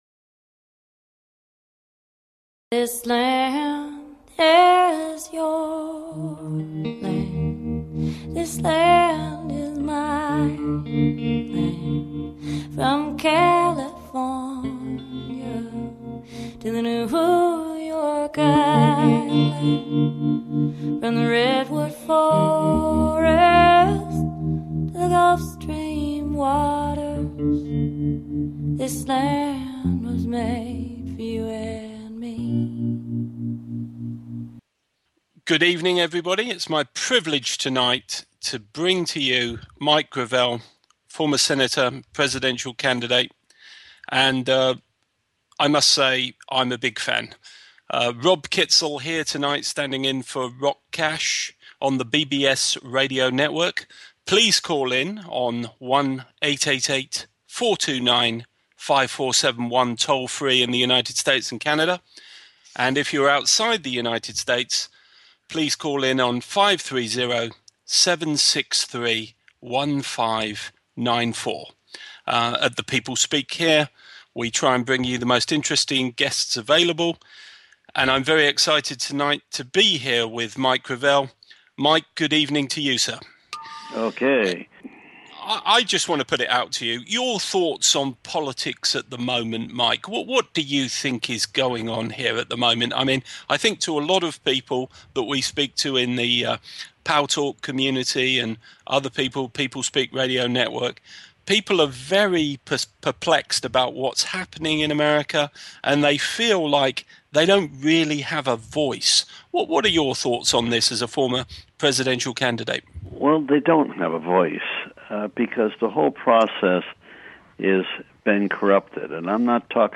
Guest, Mike Gravel
MIKE GRAVEL - Former US Senator discusses his career and exposes the truth about the US Government and its control by corporations, lobbies, Wall Street and the ongoing corruption. Mike takes auidence questions from all over the USA regarding his thoughts on legalization of Marijuana, UFO disclosure, Government policy, the restoration of law and many other issues.